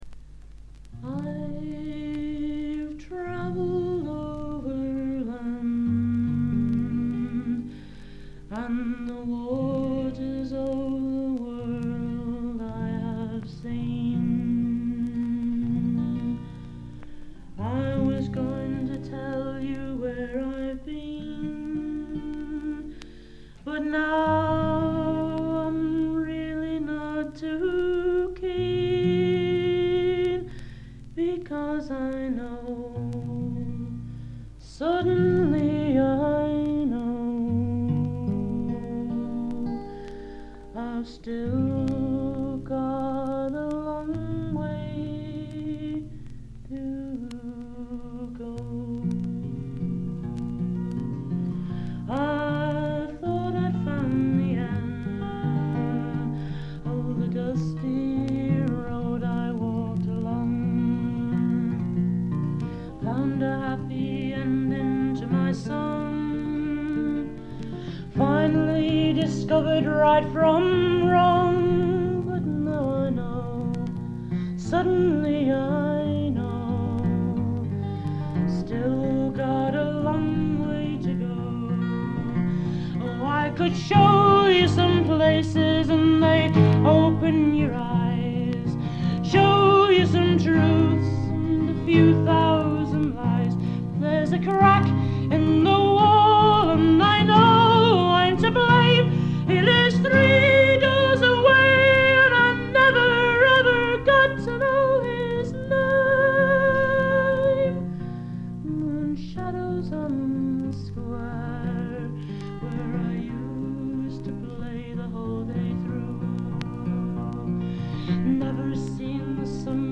軽微なバックグラウンドノイズにチリプチ少々。
内容はギター弾き語りのライブで、全11曲のうち自作3曲、カヴァー1曲、残りがトラッドという構成です。
試聴曲は現品からの取り込み音源です。